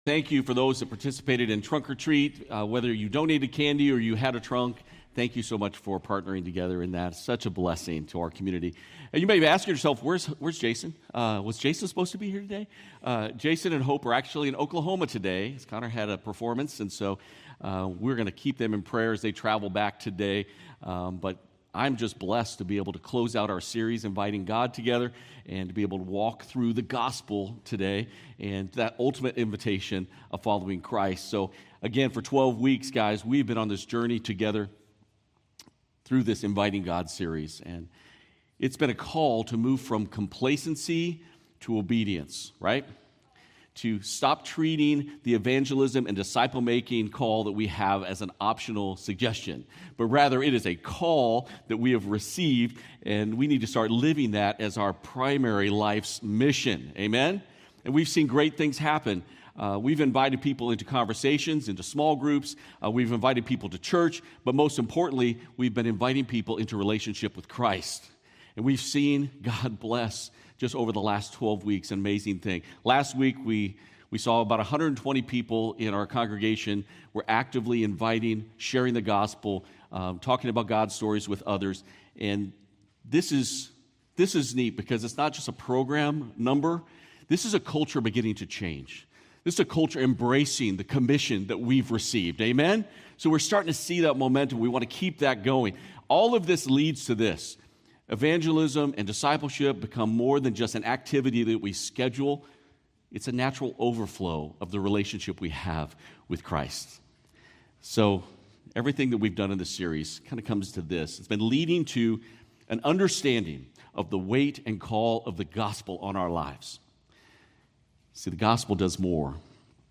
Audio podcast of the Sunday morning messages